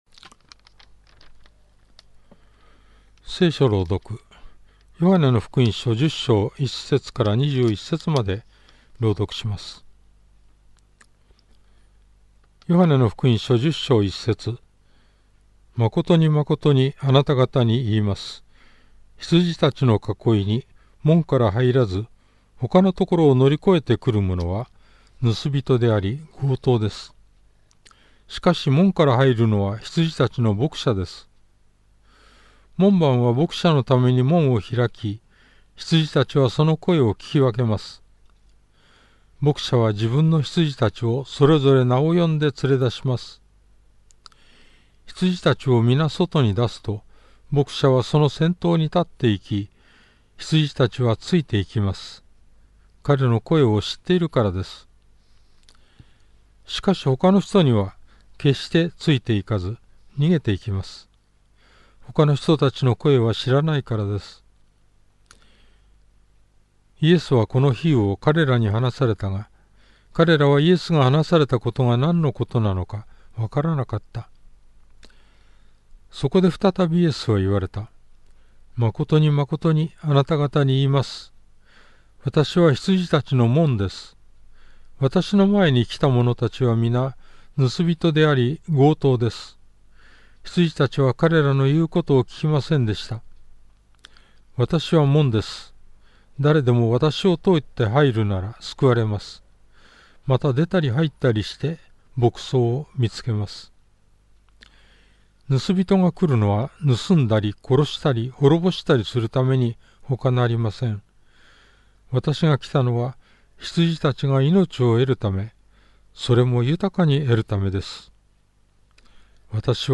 BibleReading_J10.1-21.mp3